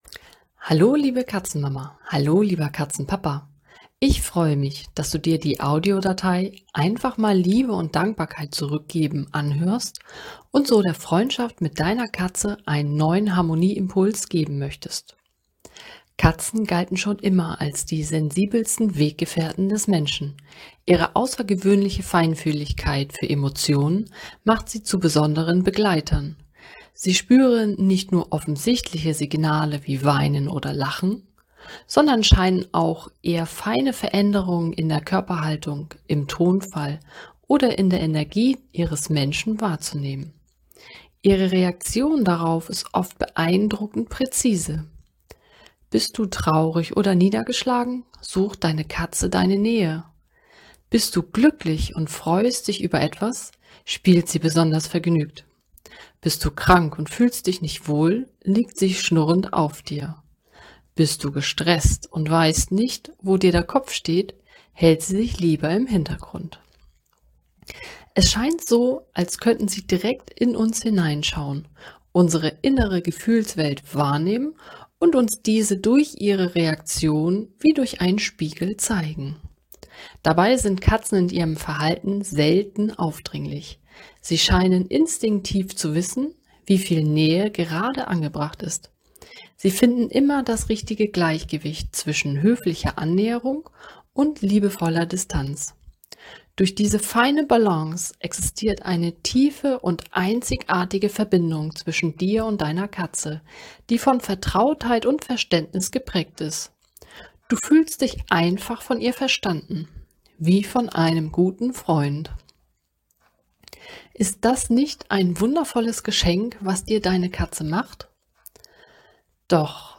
Eine geführte Audio-Visualisierung für Katzeneltern, die ihre Bindung vertiefen und ihrer Katze Liebe, Sicherheit und Dankbarkeit senden möchten.
Mit meiner sanft angeleiteten Visualisierung unterstützt du deine Katze dabei, sich gesehen, geliebt und verbunden zu fühlen – und gleichzeitig bringst du mehr Ruhe, Achtsamkeit und Wärme in eure gemeinsame Atmosphäre.